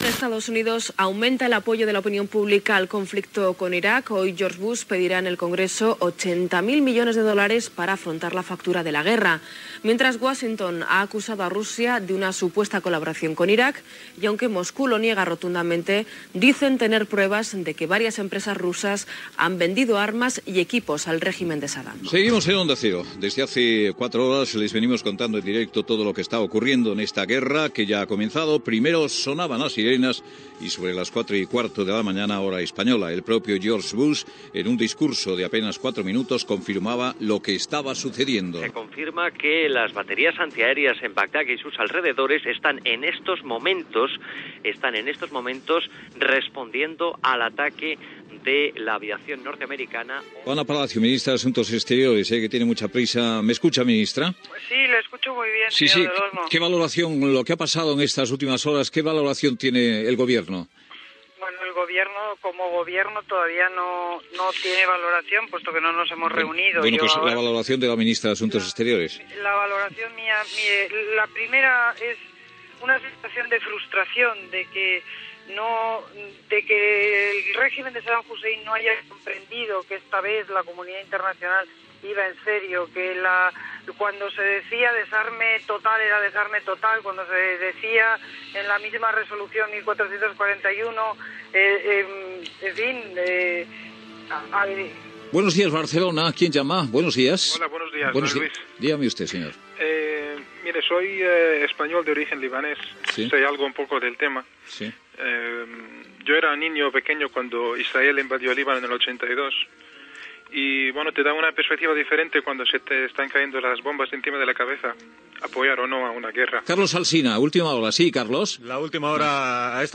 Resum informatiu de l'inici de la guerra d'Iraq, valoració de la minista d'Assumptes Exteriors espanyola Ana Palacio, trucada telefònica, informació de la situació
Info-entreteniment